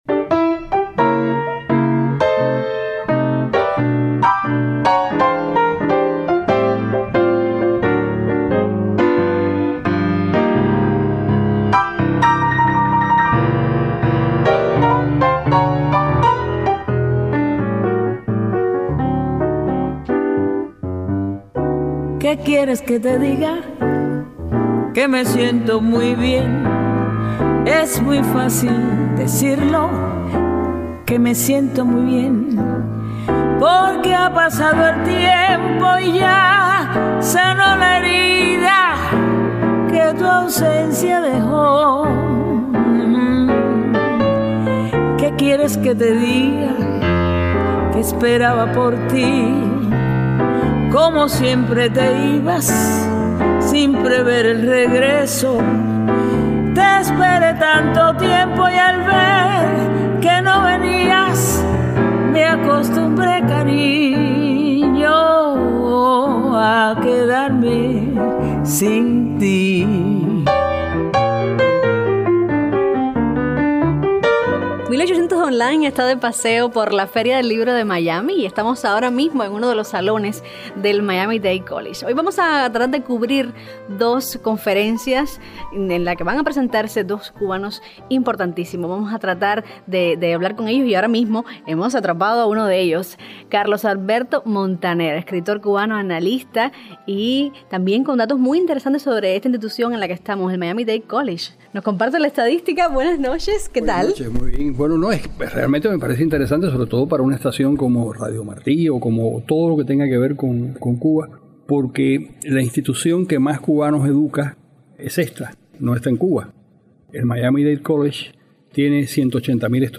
Estamos de gala con la entrevista de Carlos Alberto Montaner en la Feria del Libro en Miami Dade College